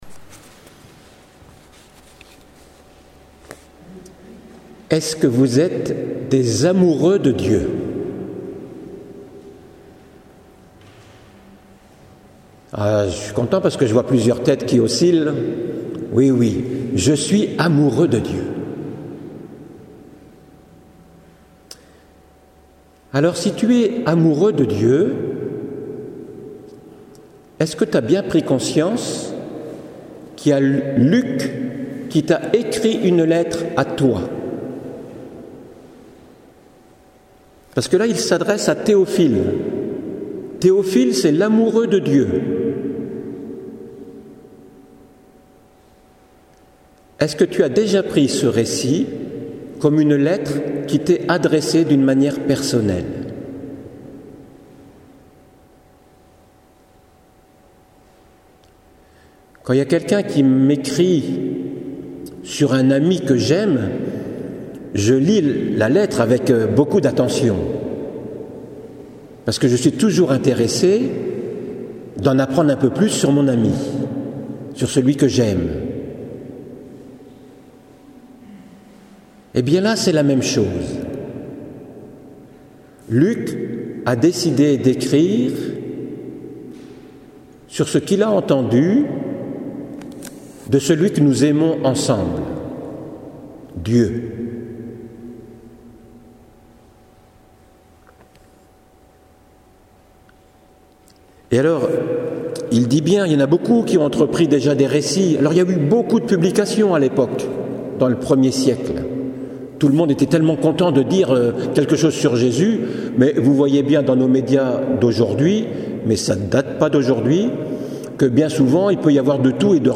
homélie lettre à un amoureux
voici l’homélie en version vocale =>